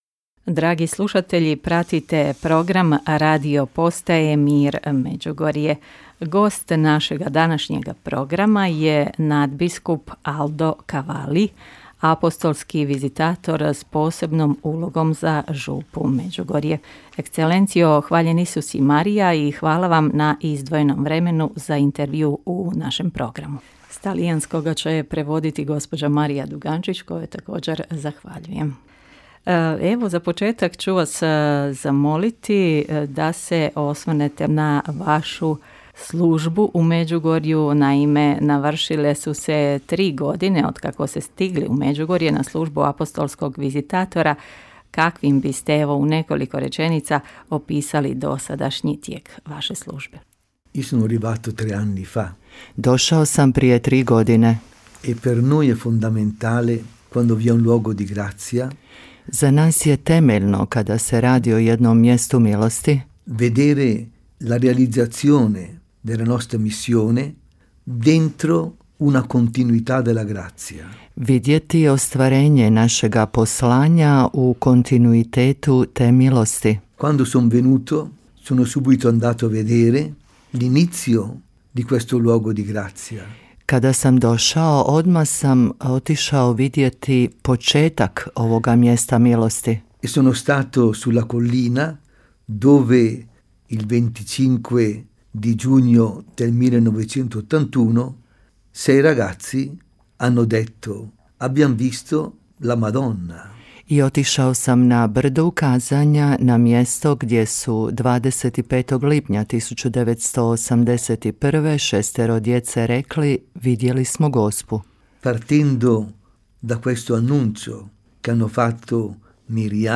Bio je to povod za gostovanje nadbiskupa Alde Cavallija, apostolskog vizitatora s posebnom ulogom za župu Međugorju u našem radijskom programu.